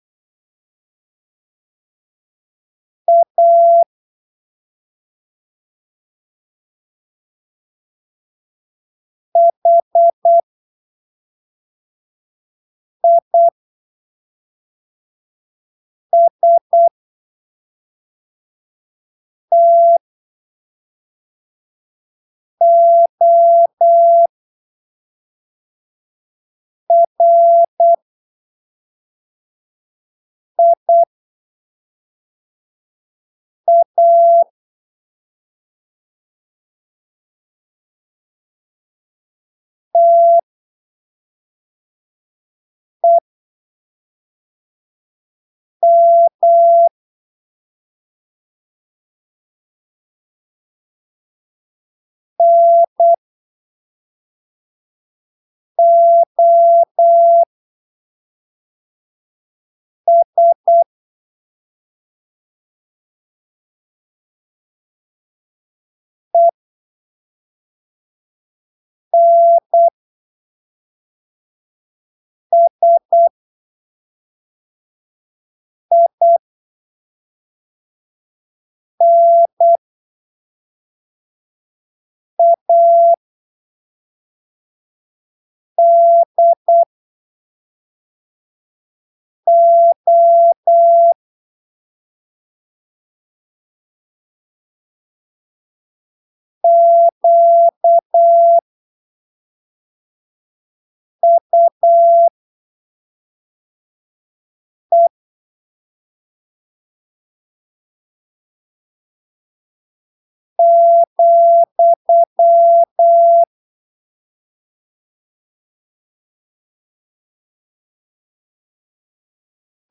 em em uma velocidade aproximada de "8ppm" com um espaçamento
bem generoso entre os caracteres. Você vai encontrar o texto em
Texto CW em áudio  05              Texto correção 05